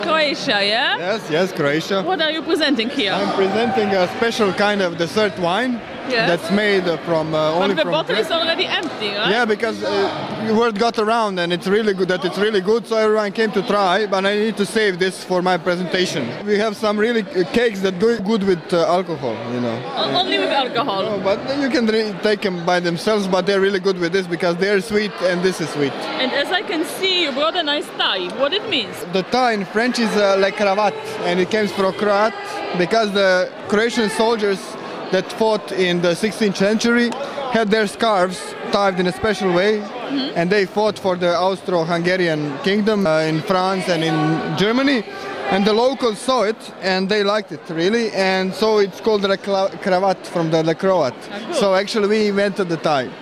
Mówią uczestnicy Forum